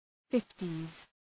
Shkrimi fonetik {‘fıftız}